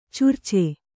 ♪ curce